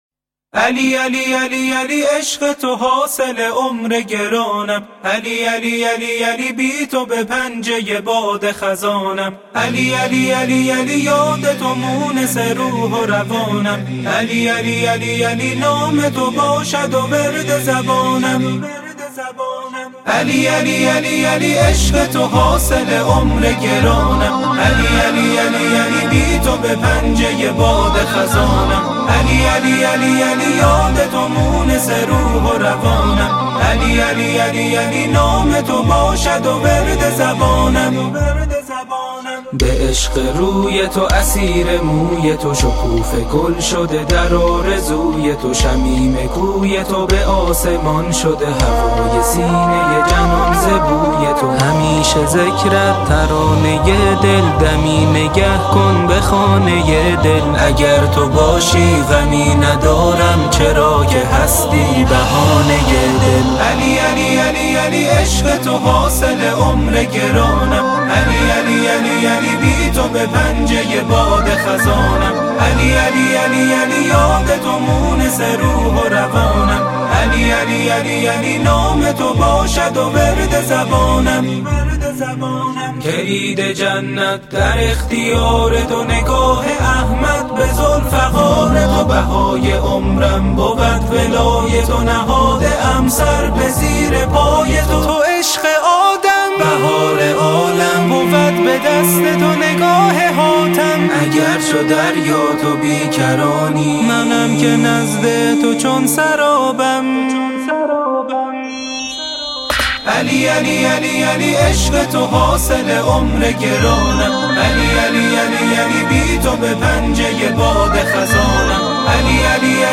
مولودی زیبا و دلنشین